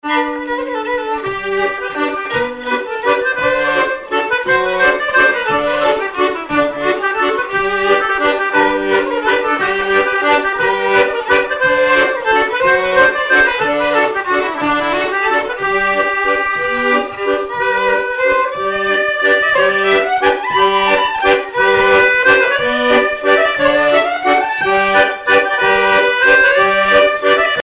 Miquelon-Langlade
danse : mazurka-valse
violon
Pièce musicale inédite